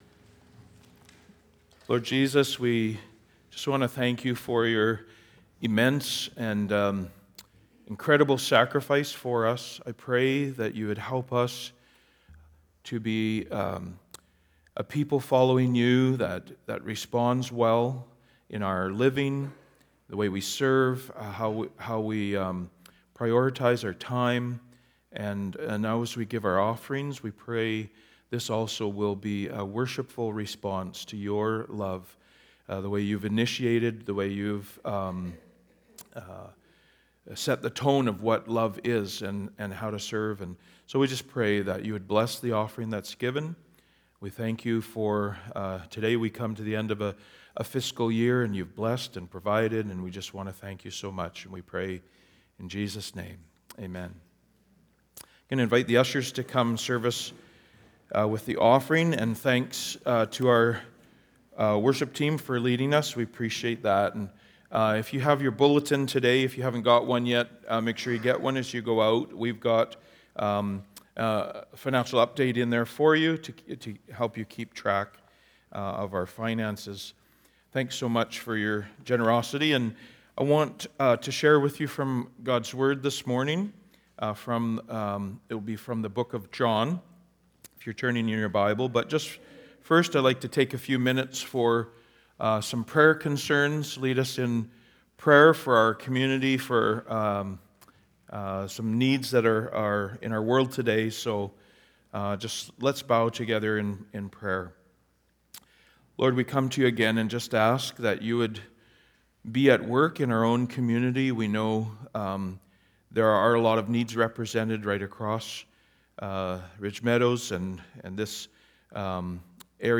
Sermons | Cornerstone Neighbourhood Church